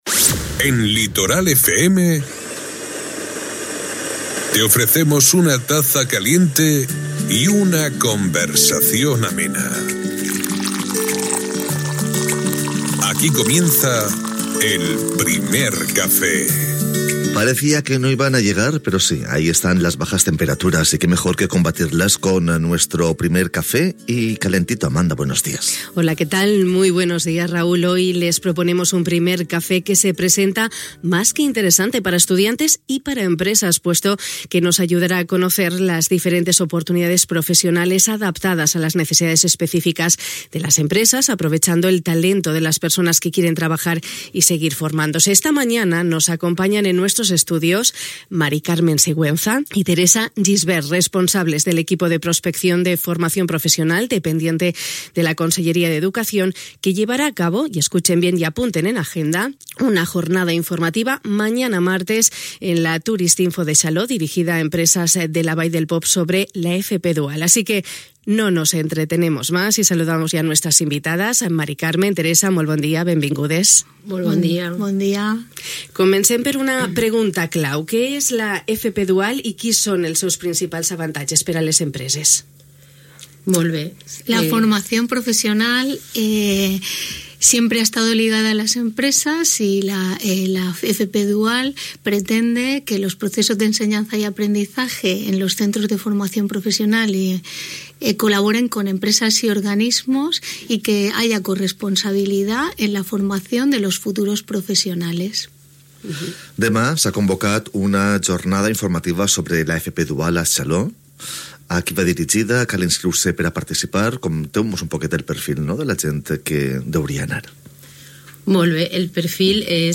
Amb l'objectiu de saber més sobre la Formació Professional Dual i les seves avantatges, hem conversat aquesta matí, al Primer Cafè de Ràdio Litoral, amb dos responsables de l'equip de Prospecció de Formació Professional (FP) que duren a terme la jornada,